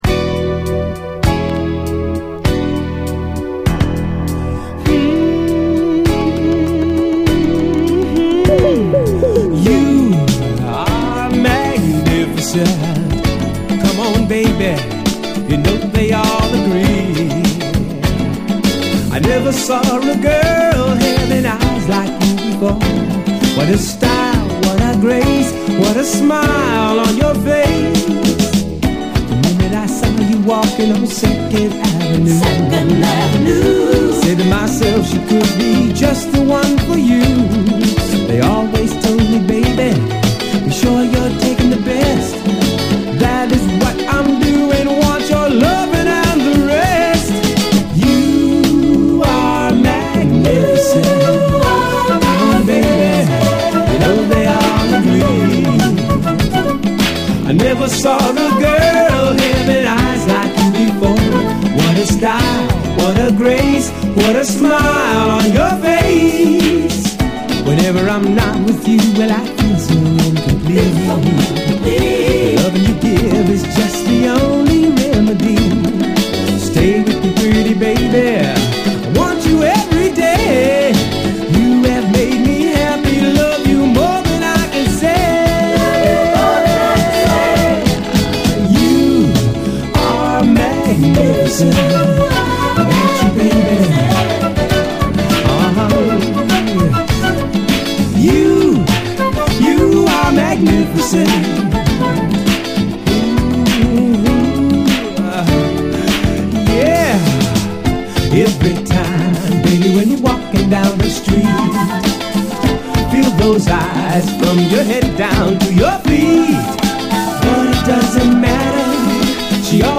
SOUL, 70's～ SOUL
ギター・カッティングが気持ちいい、完璧なユーロ・モダン・ソウル